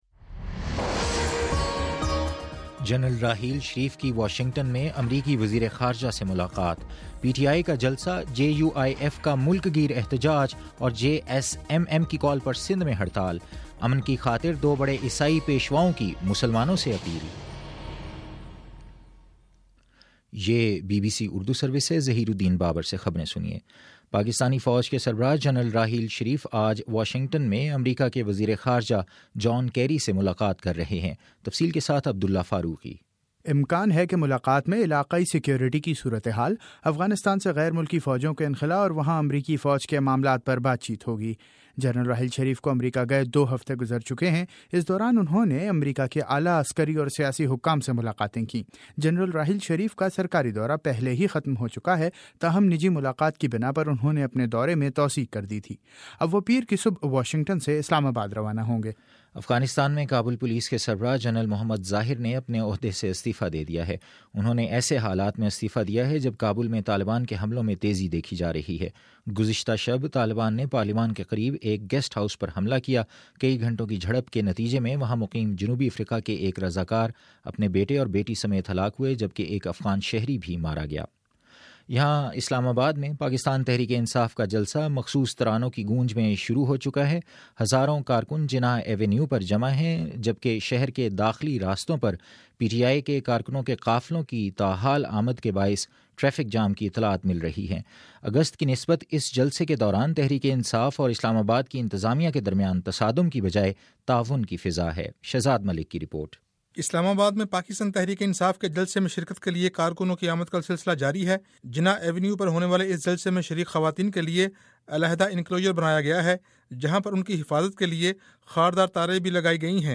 نومبر30 : شام سات بجے کا نیوز بُلیٹن